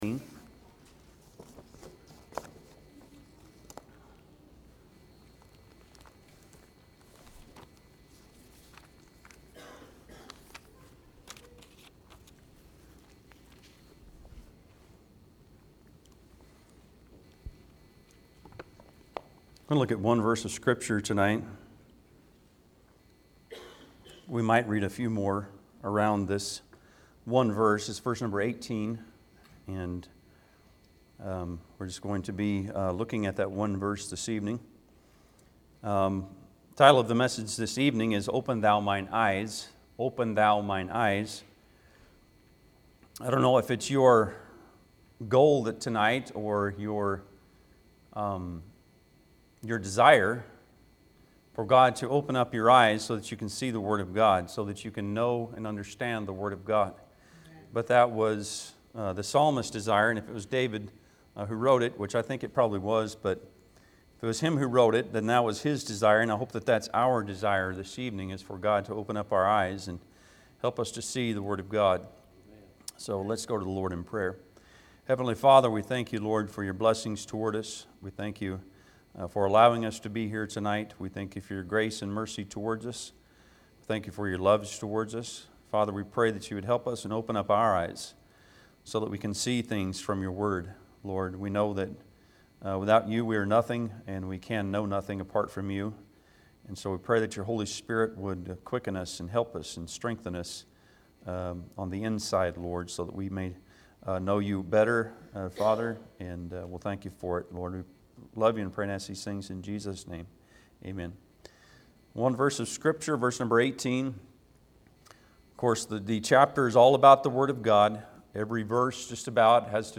Psalms 119:18 Service Type: Sunday pm Bible Text